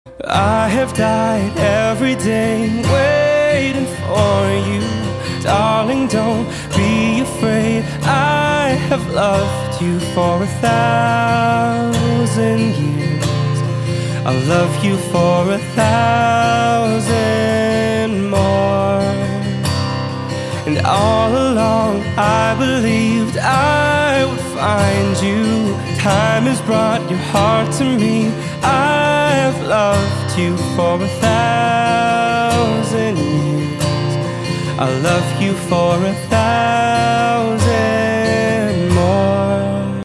M4R铃声, MP3铃声, 欧美歌曲 71 首发日期：2018-05-13 09:24 星期日